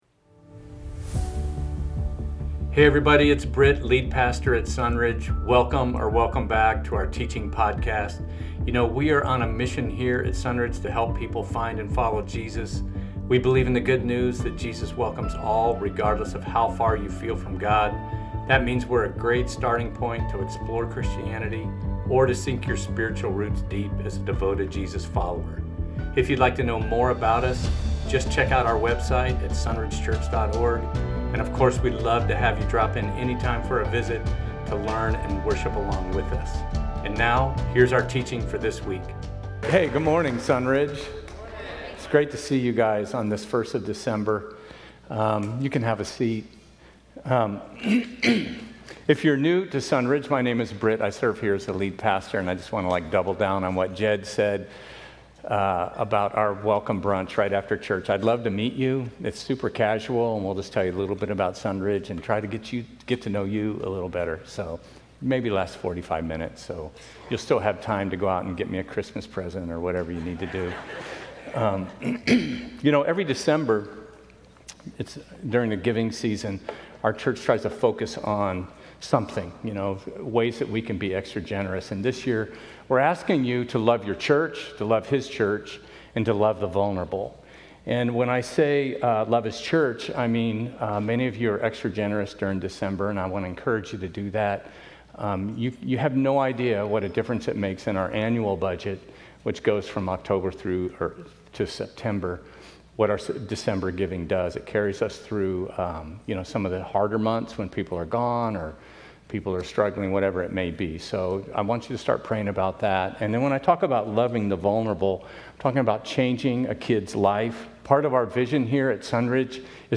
Sermon Audio